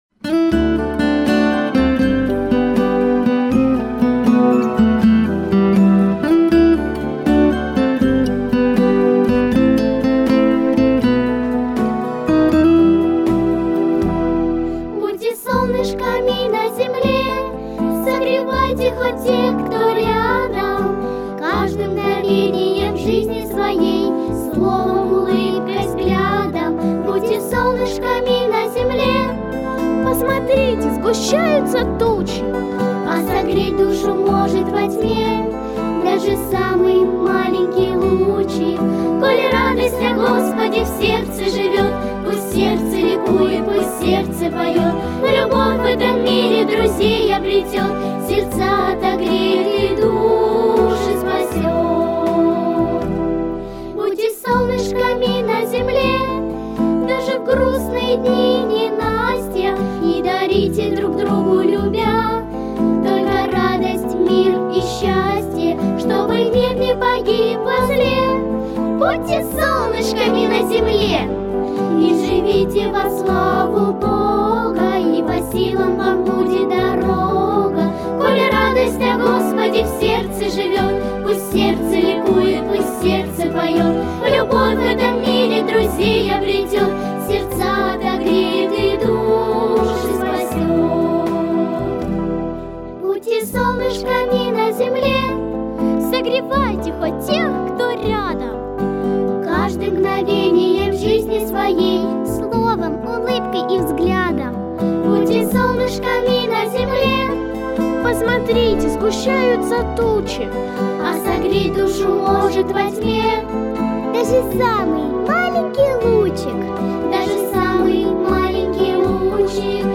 • Категория: Детские песни
🎶 Детские песни / Песни на праздник / Христианские Песни ⛪